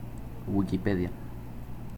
Summary Description Es-Wikipedia.ogg English: Pronunciation in Spanish of "Wikipedia". Male voice, Puerto Rican accent.
Voz masculina con acento puertorriqueño.